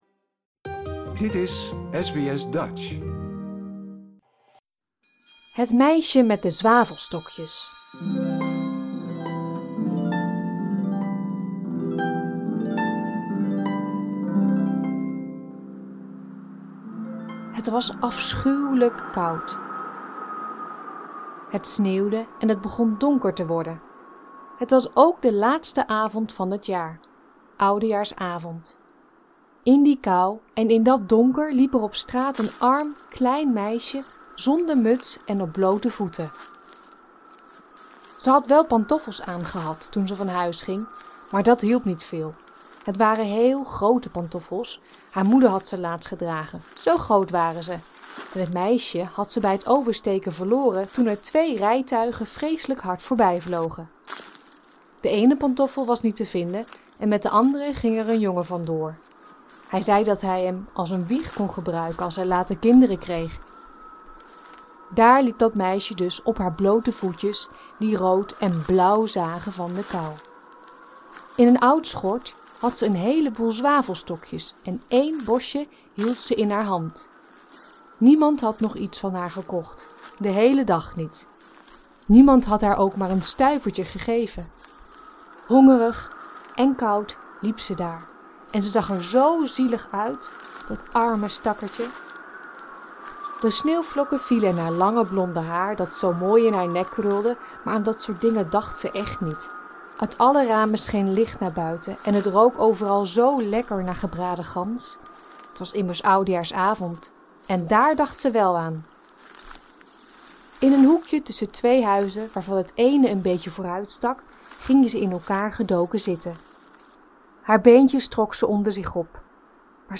Voorgelezen: Het Meisje met de Zwavelstokjes